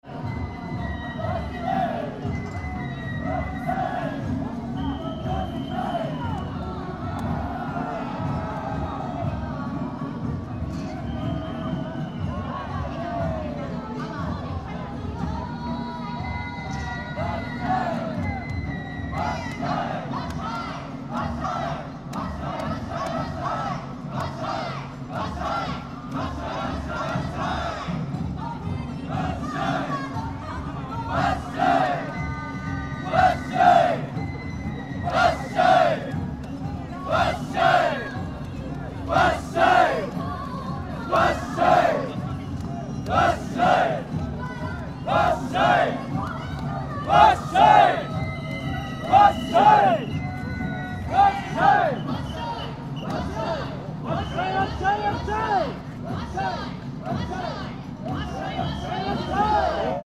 In front of Machi-naka Square
As the opening of the parade, the O-Waraji (Japanese Big Sandal) came in front of Machi-naka Square.